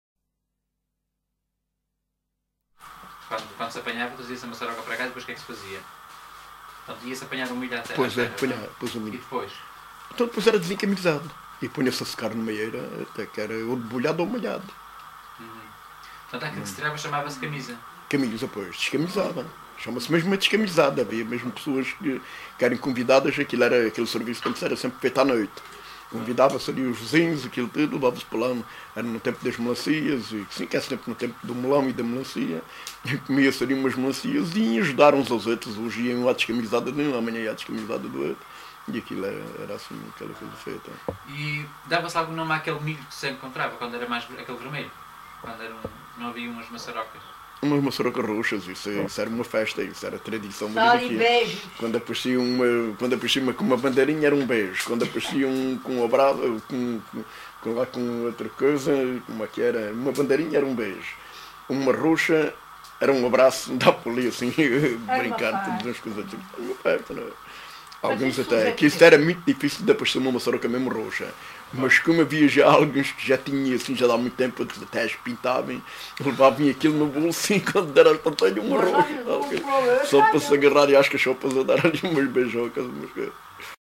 LocalidadeSapeira (Castelo de Vide, Portalegre)